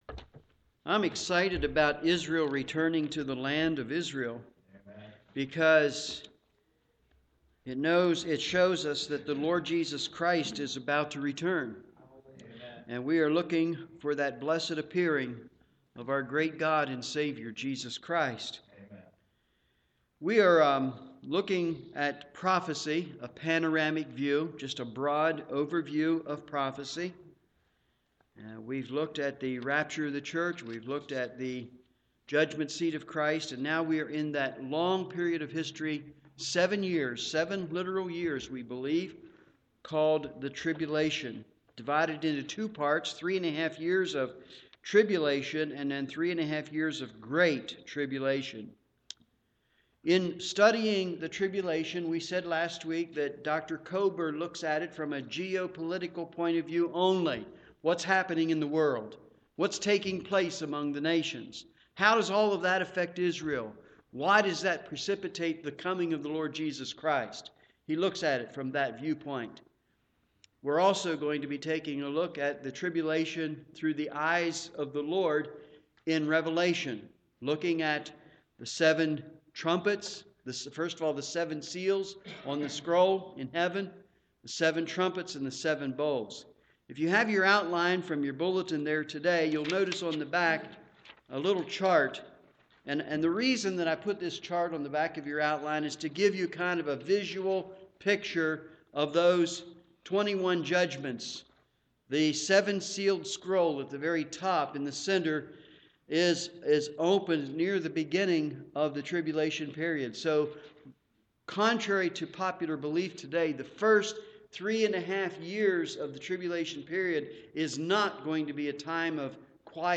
Evening Service
Sermon